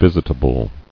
[vis·it·a·ble]